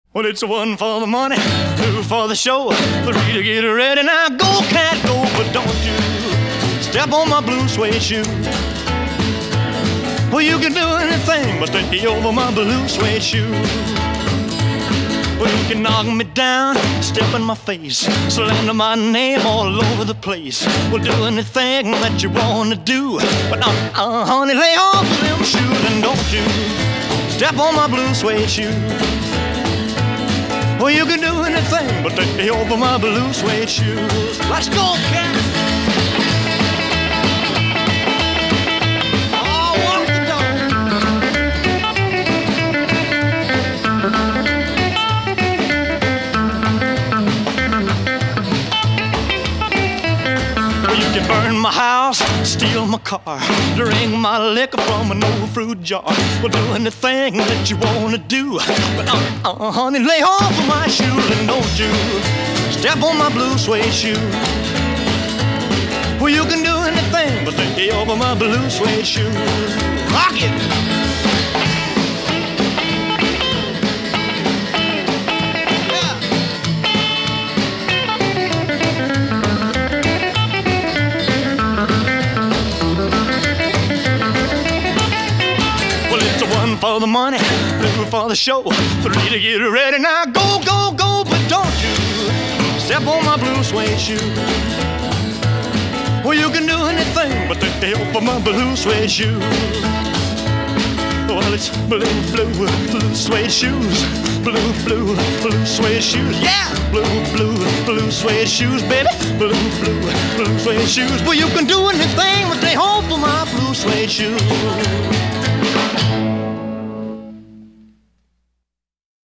это энергичная рок-н-ролльная песня